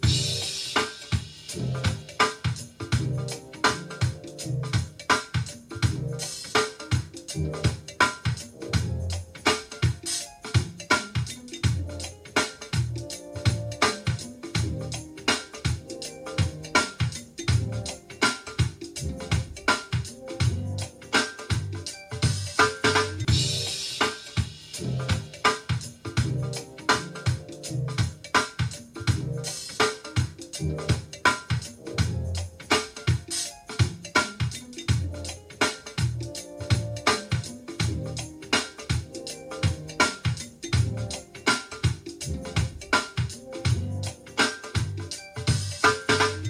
gogo-drums.mp3